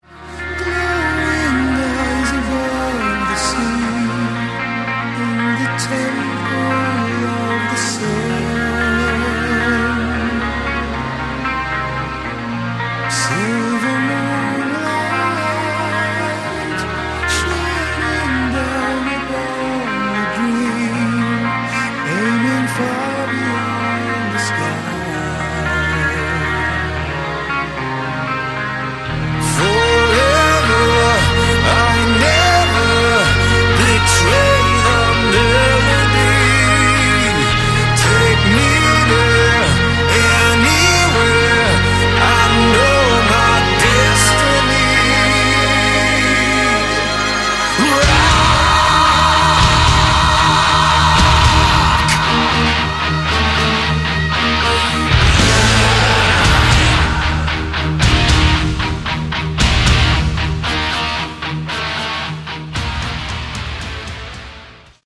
Category: Melodic Metal
vocals
drums
bass
guitars